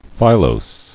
[fi·lose]